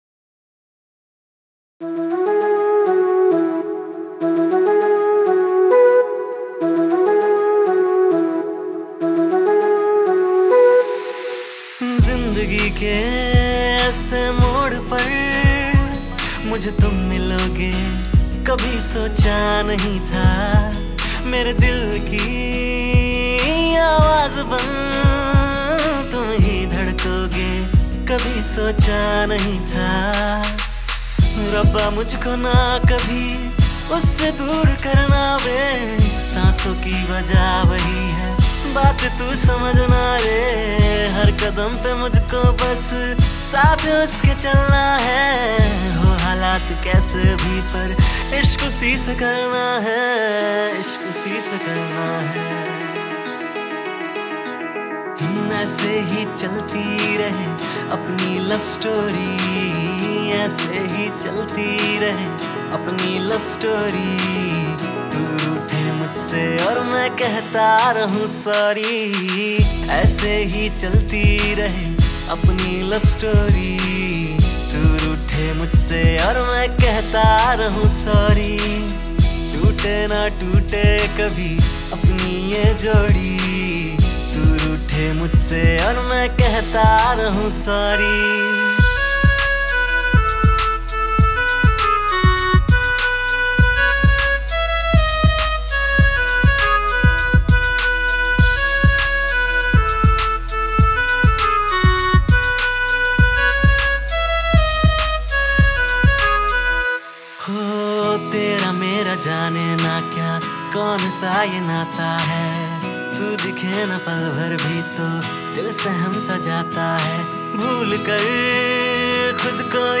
New Punjabi Song - Latest punjabi song